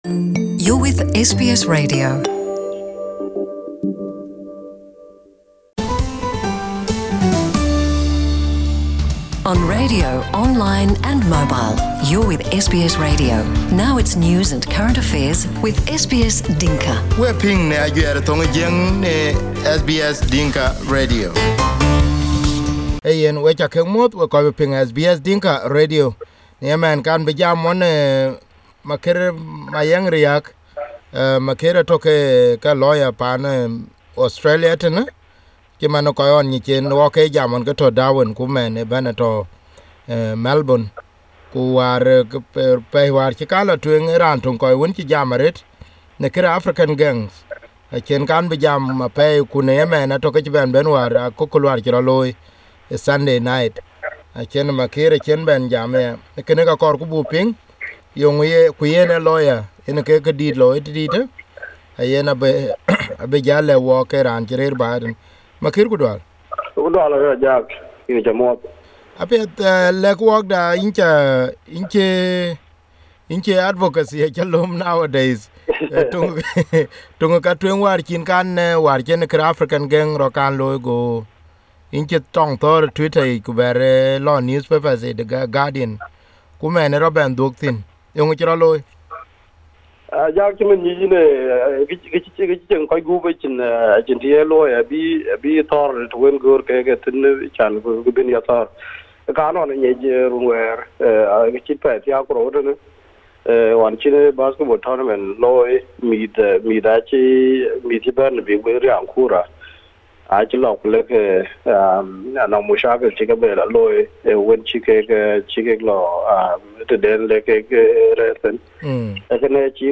Here is the interviewed in Dinka.